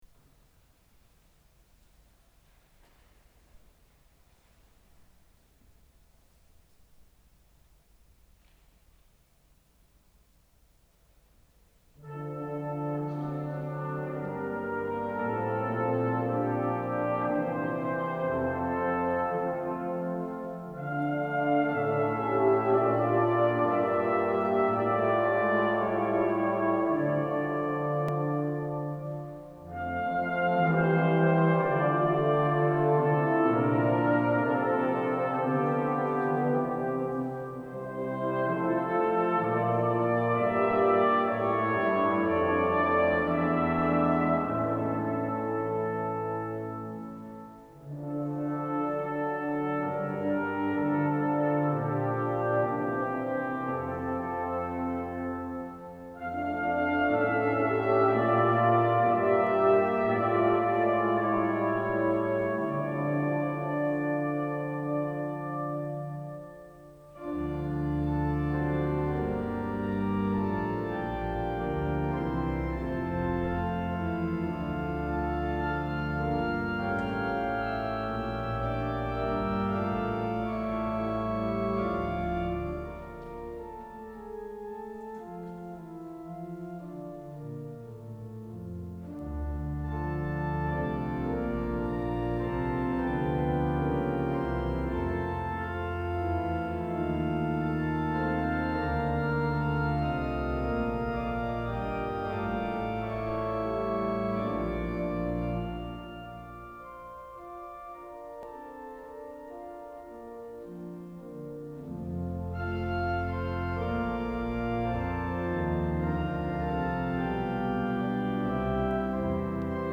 Orgelkonsert
orgelsymfoni Ess-dur
Vasakyrkans orgel i Göteborg
blåsensemble